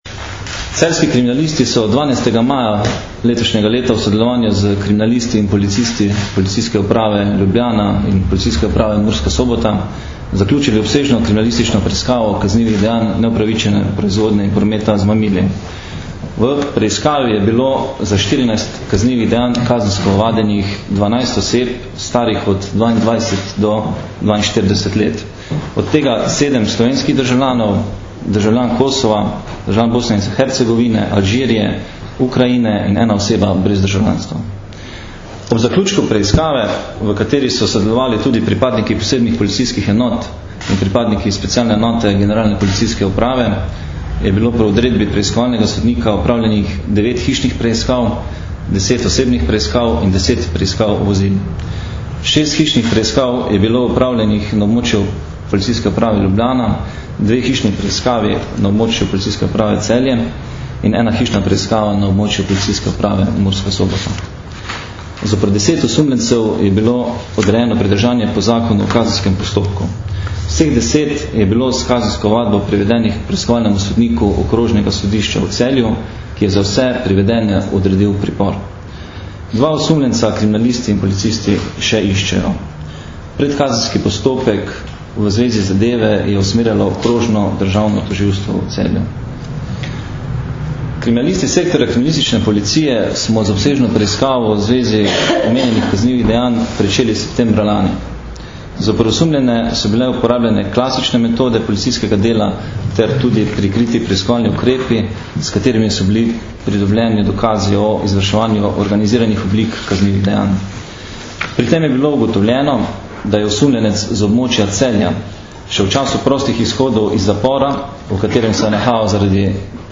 Policija - Zaradi tihotapljenja prepovedanih drog policisti ovadili 21 oseb in razkrili delovanje več kriminalnih združb - informacija z novinarske konference
Zvočni posnetek izjave